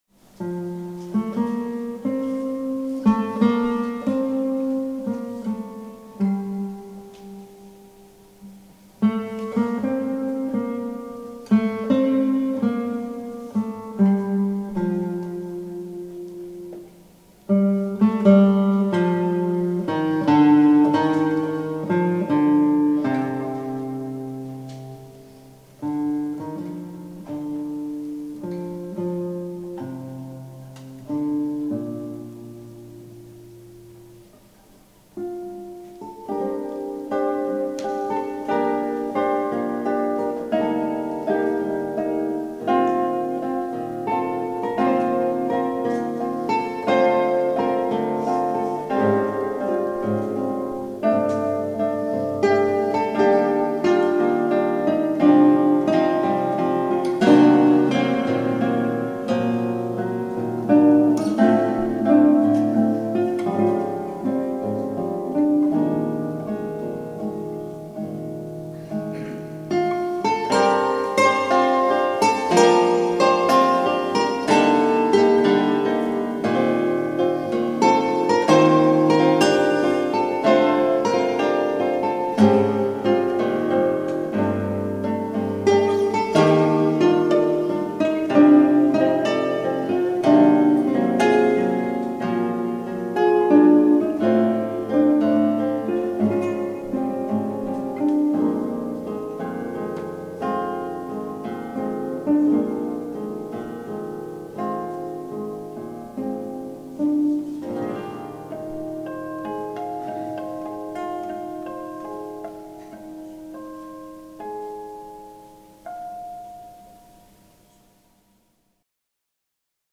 for 3 guitars | per 3 chitarre